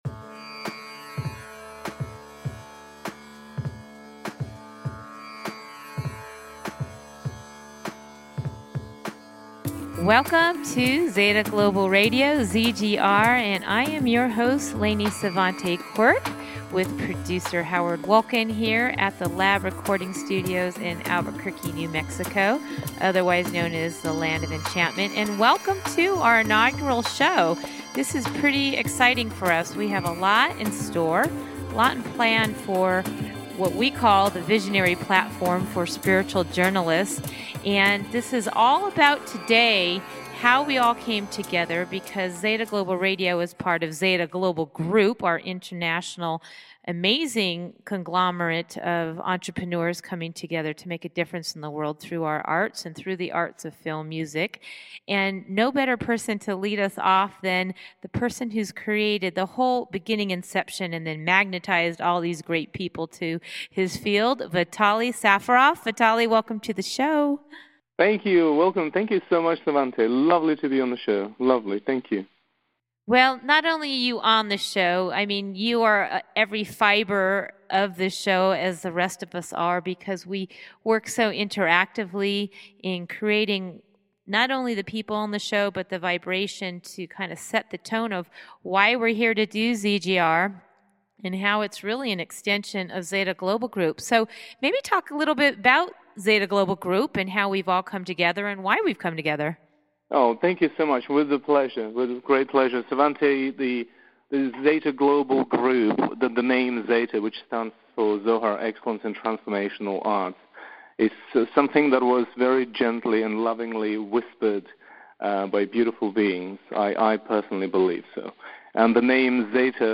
Headlined Show, Zeta Global Radio ZGR April 24, 2015